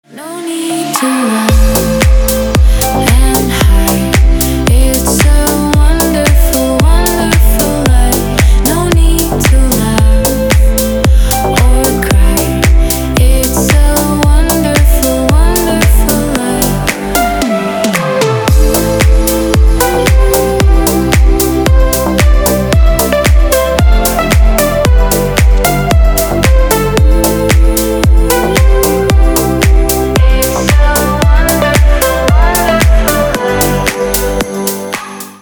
ремикс 2025 для звонка